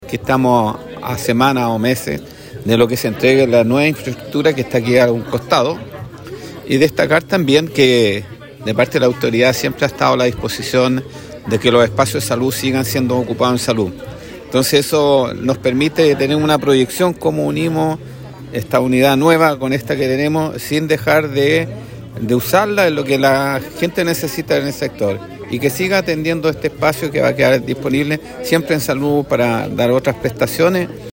Con una emotiva ceremonia que reunió a autoridades, profesionales de la salud y vecinos del sector poniente de Curicó, el Centro Comunitario de Salud Familiar (Cecosf) Prosperidad conmemoró un nuevo aniversario, reafirmando su rol como referente en la atención primaria.